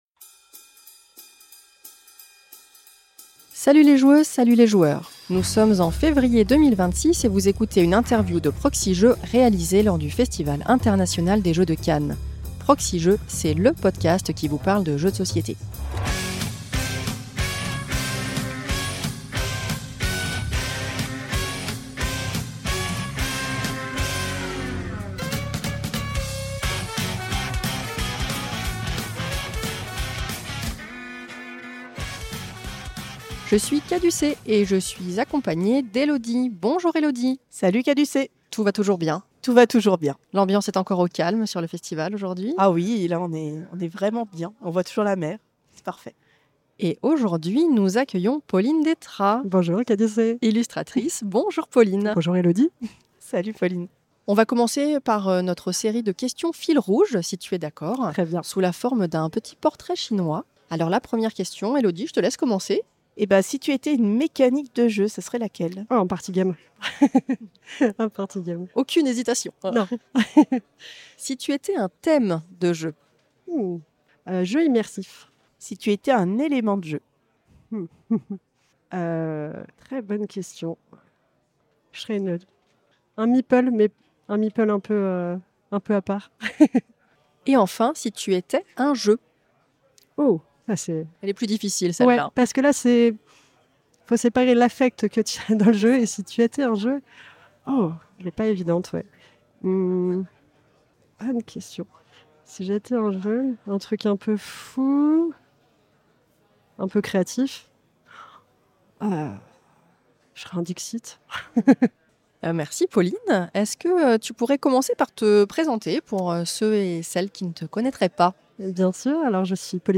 Interview
réalisée lors du Festival International des Jeux de Cannes 2026